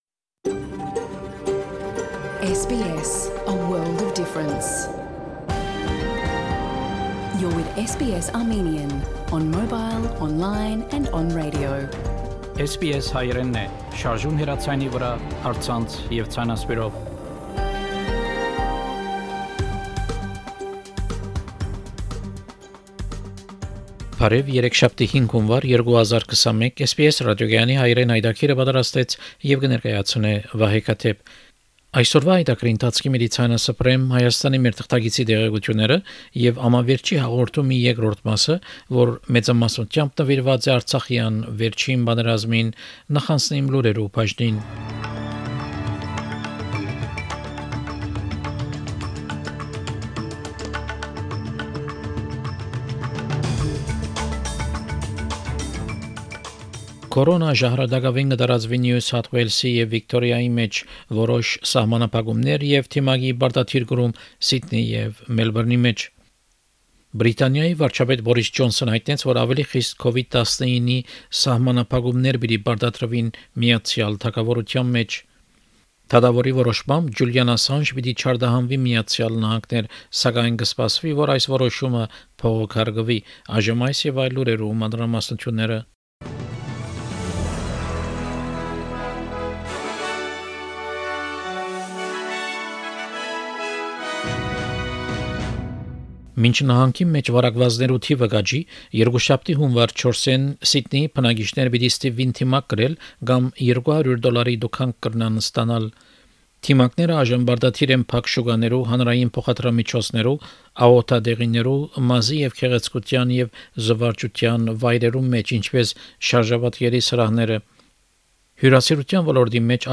SBS Armenian news bulletin – 5 January 2021